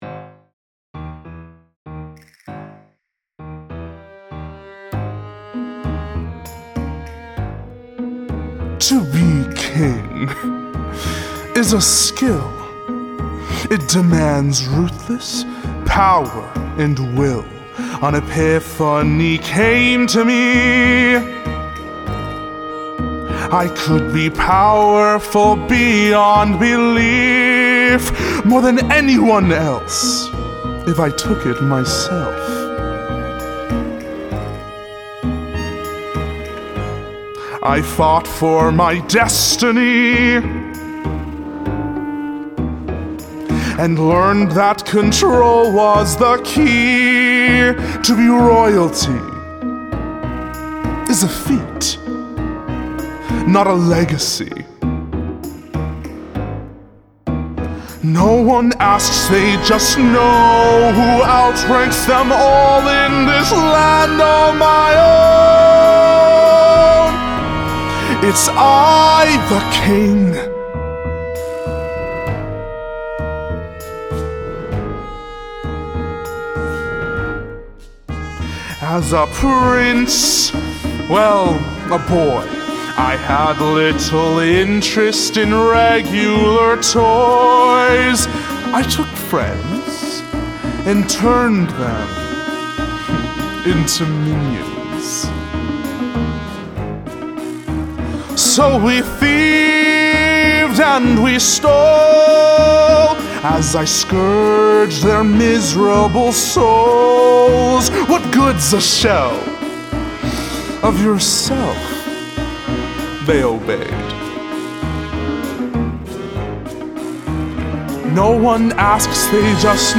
A NEW MUSICAL